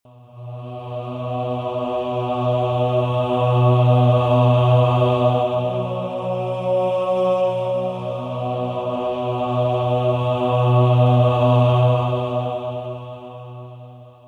Мужской хор для сборки